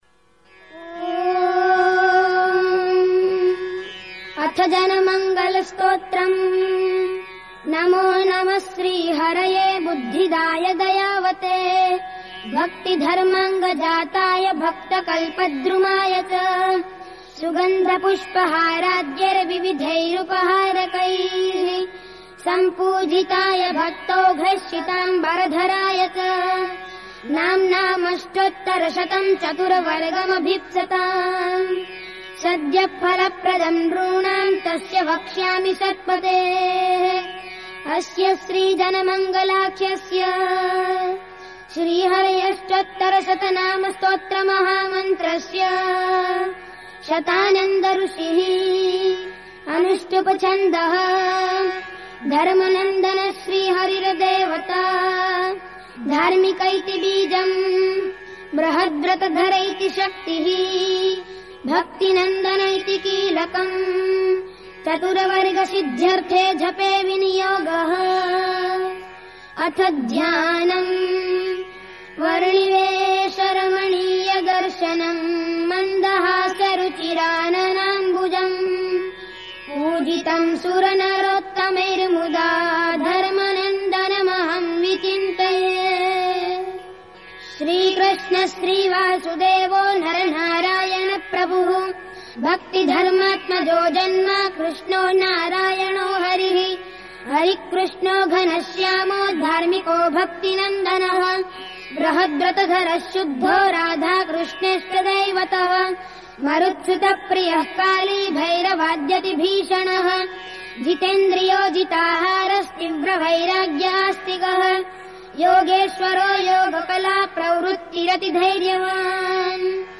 00-Janmanagal-stotra-Original1-swaminarayan-kirtan.mp3